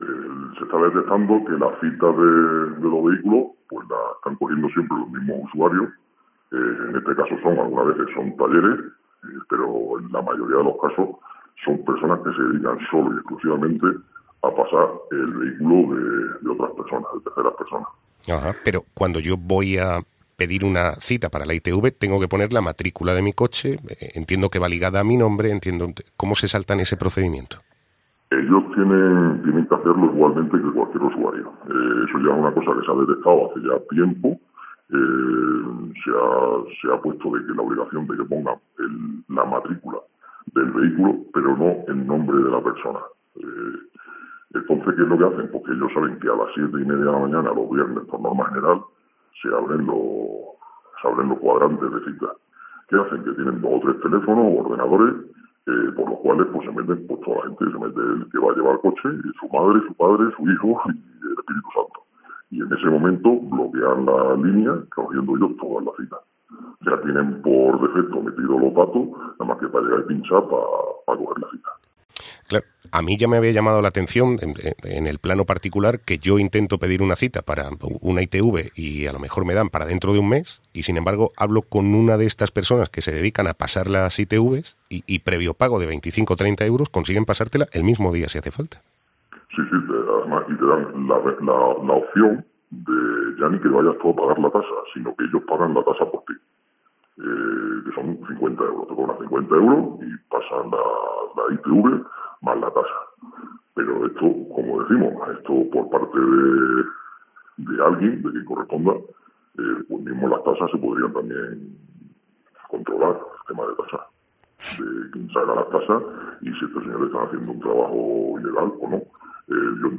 Entrevista | Técnico de ITV pública de Extremadura